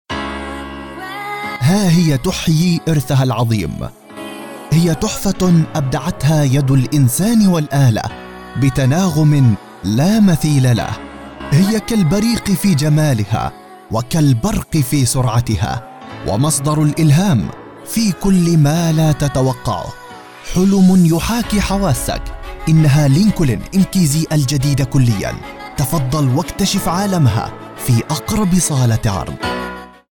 Mercedes Benz ad